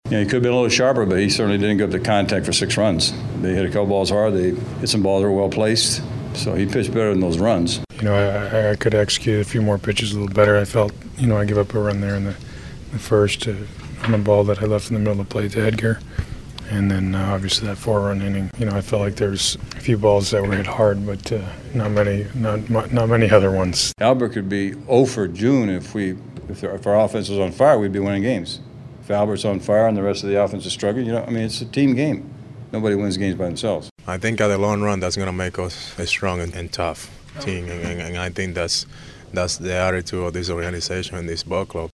Cardinals postgame Share this: Facebook Twitter LinkedIn WhatsApp Email